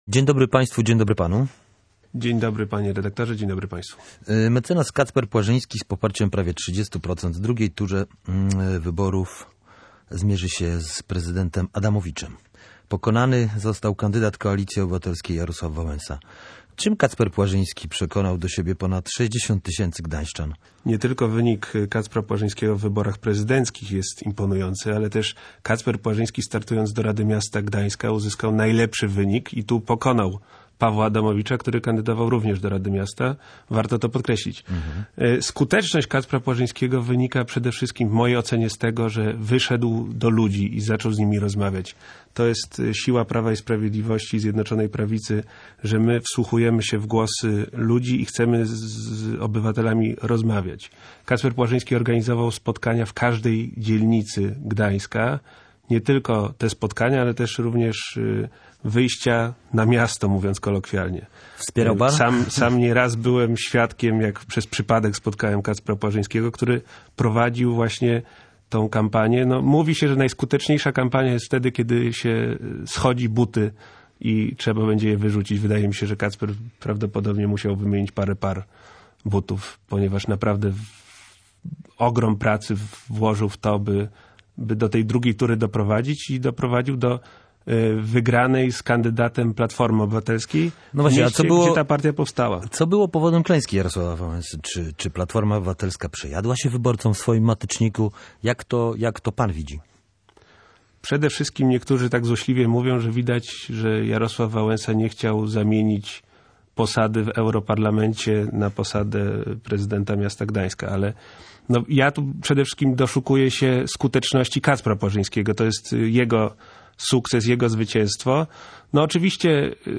Wyniki wyborów samorządowych, kampania Kacpra Płażyńskiego i cele nowego radnego Gdańska – to tematy rozmowy z popołudniowym Gościem Dnia Radia Gdańsk.